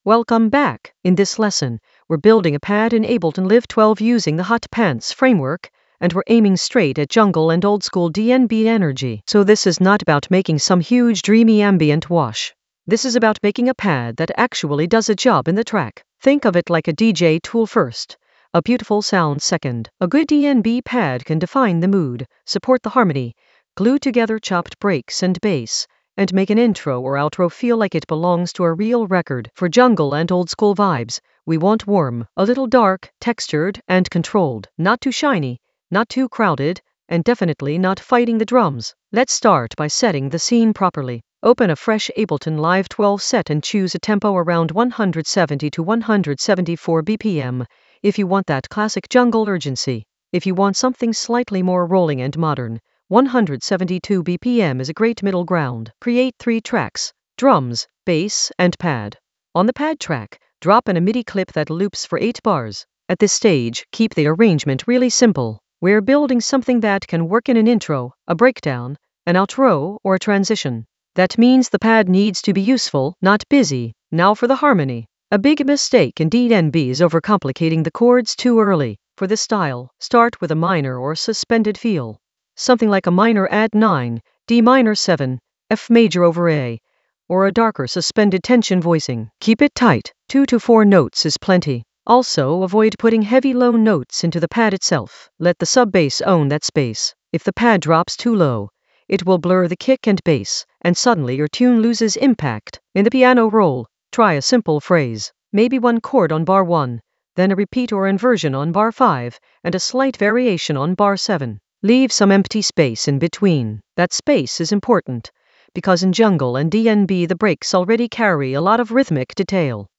An AI-generated intermediate Ableton lesson focused on Hot Pants framework: pad compose in Ableton Live 12 for jungle oldskool DnB vibes in the DJ Tools area of drum and bass production.
Narrated lesson audio
The voice track includes the tutorial plus extra teacher commentary.